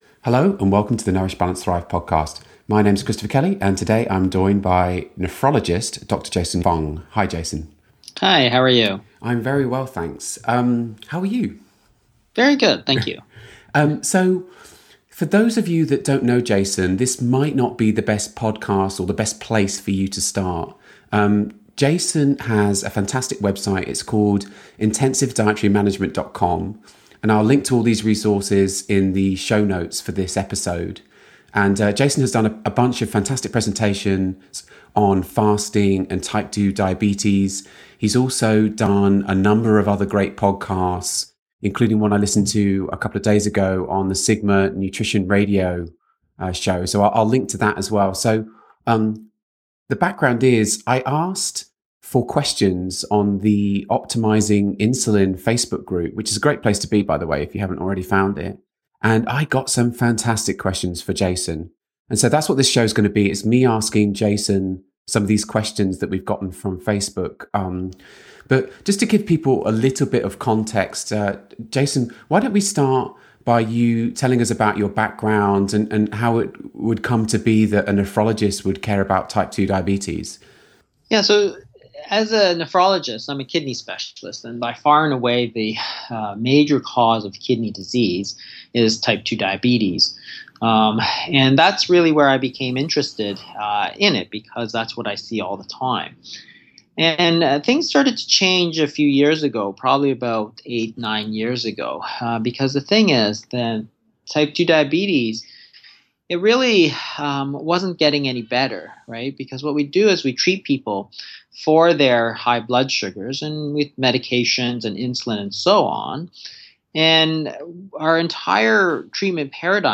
Today’s podcast episode is a bit different than usual because I’ve solicited questions from the Optimising nutrition, managing insulin Facebook group for kidney expert Dr. Jason Fung.